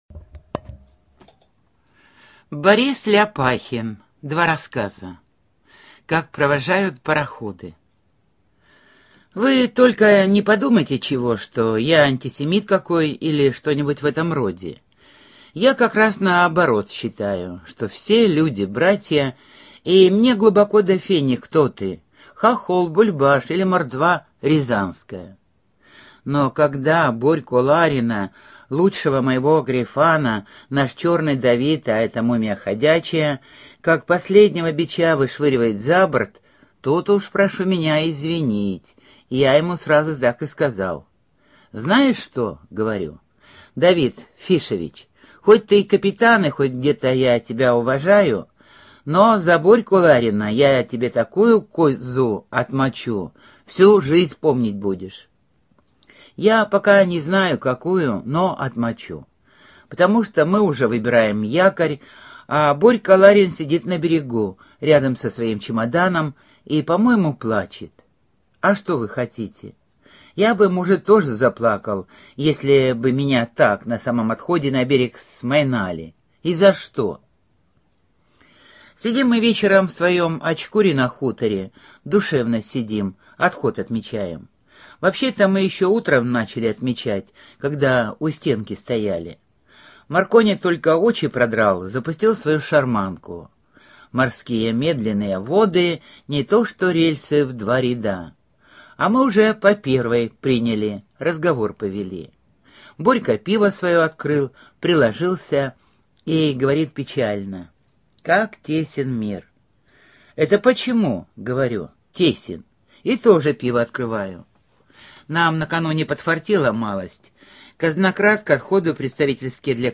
Студия звукозаписиХабаровская краевая библиотека для слепых